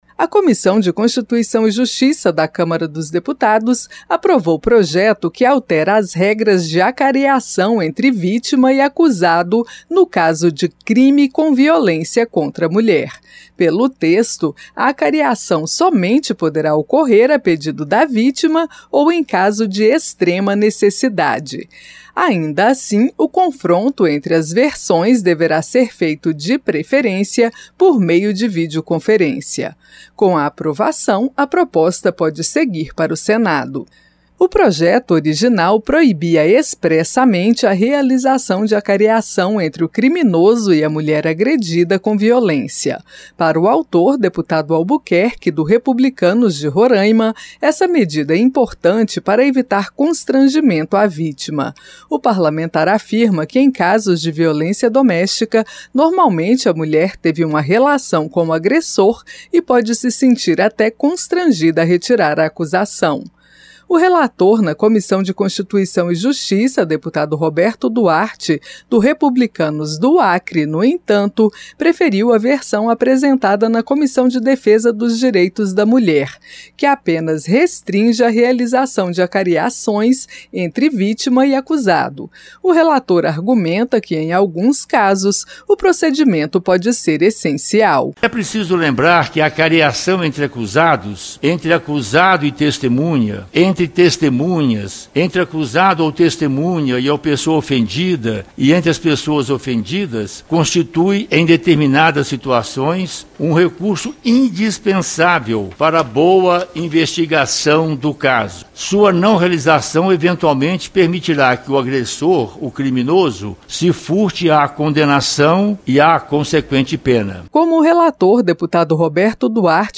CONSTITUIÇÃO E JUSTIÇA CONCLUI ANÁLISE DE PROJETO QUE MUDA REGRAS DE ACAREAÇÃO EM CRIMES COM VIOLÊNCIA CONTRA A MULHER. A REPÓRTER